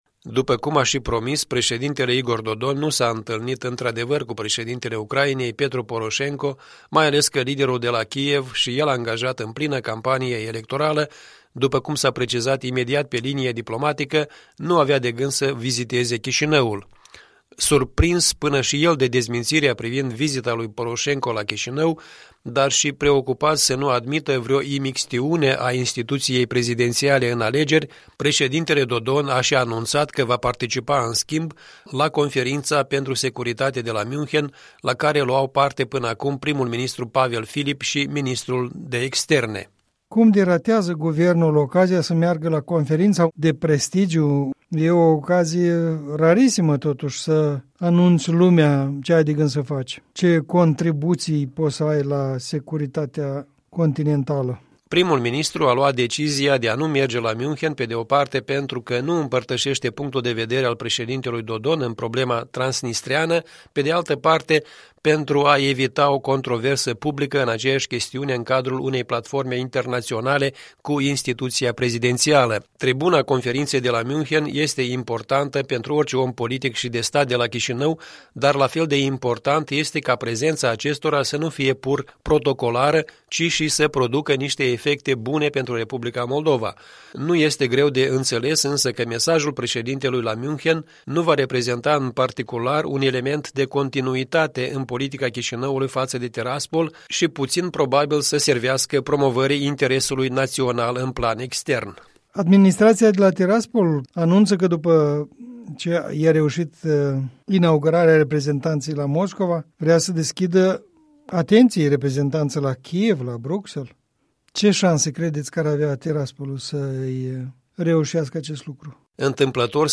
Punct de vedere săptămânal în dialog.